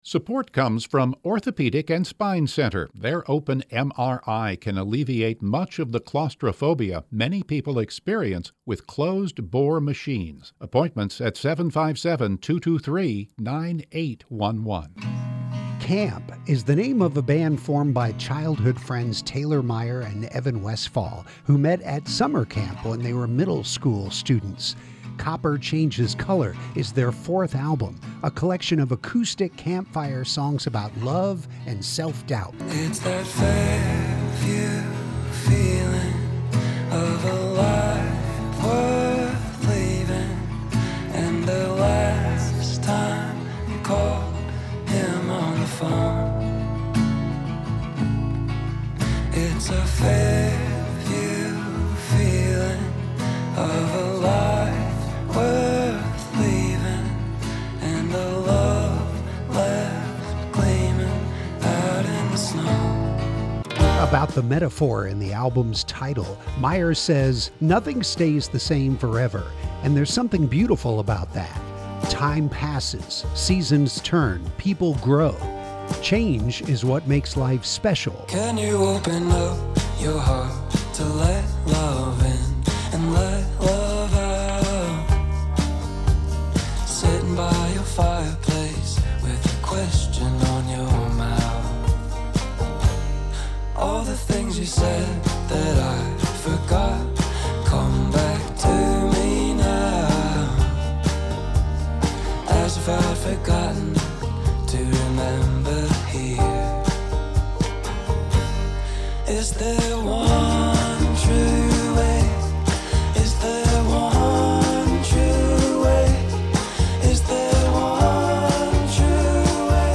All week, on each edition of Out of the Box, listen for songs from one of the best albums that’s just hitting stores